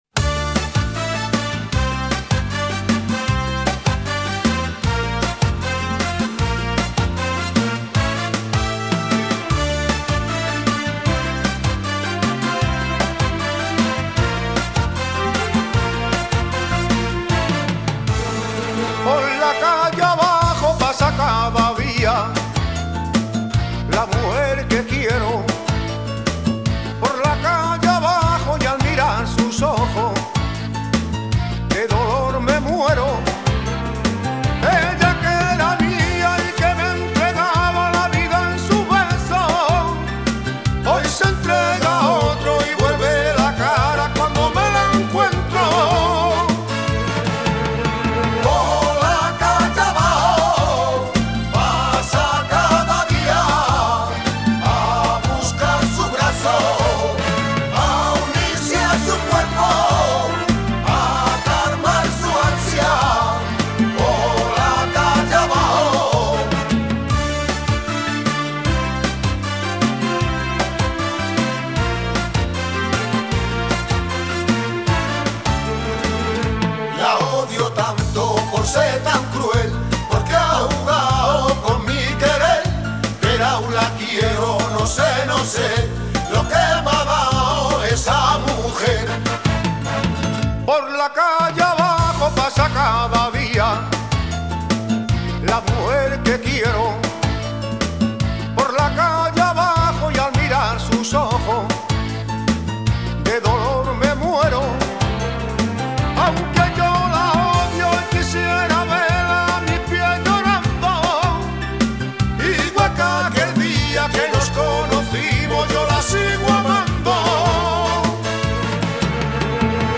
Iniciamos la semana a ritmo de rumba